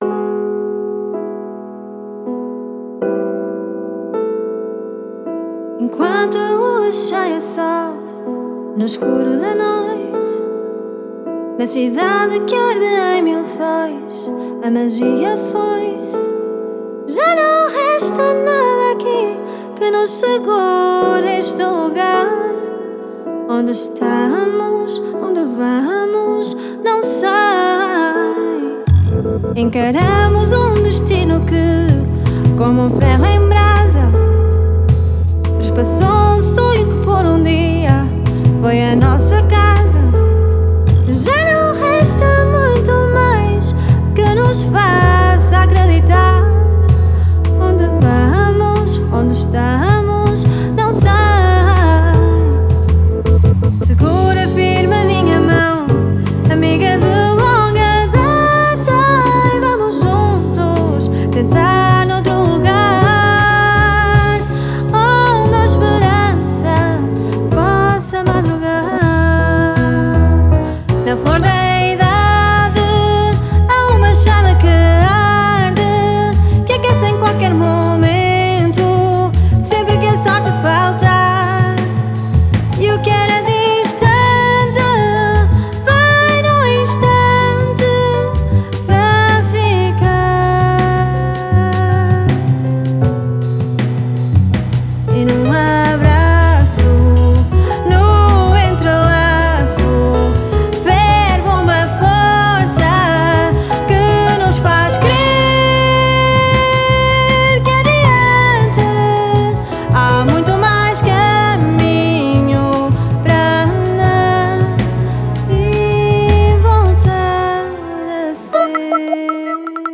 vocals
instrumental